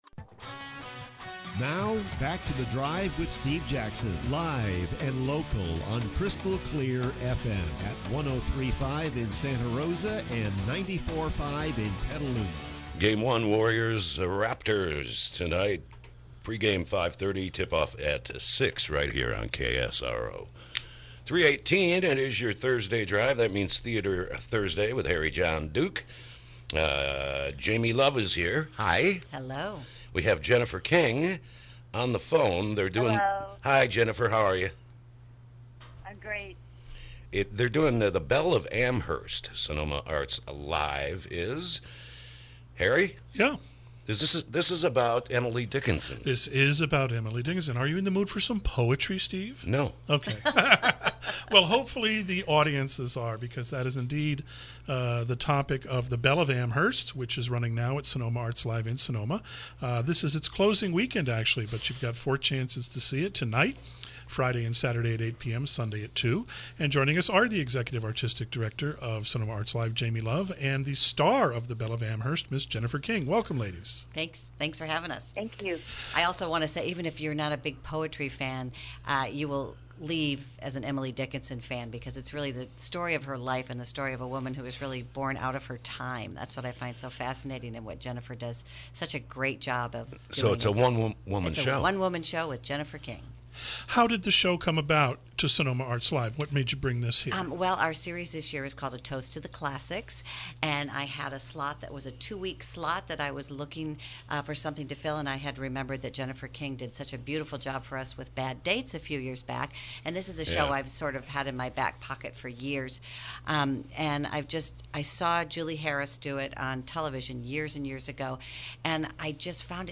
KSRO Interview: “The Belle of Amherst”